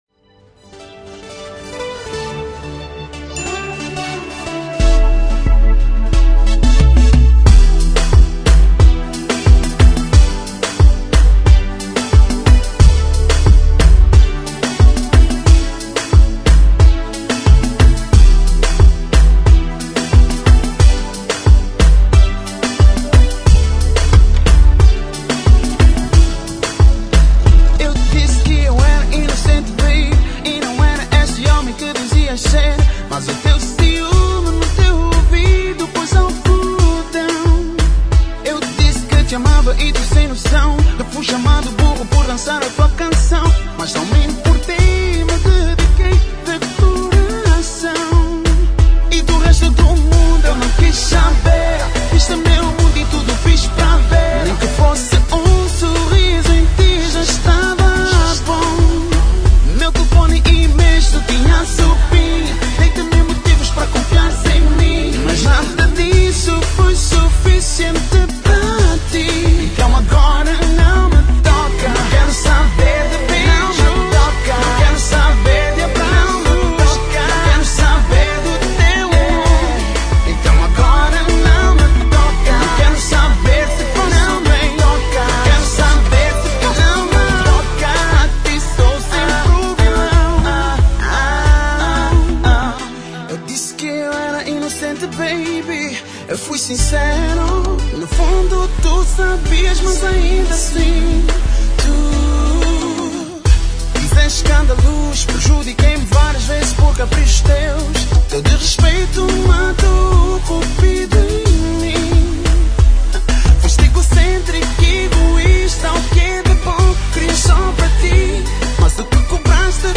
Entre-Kizomba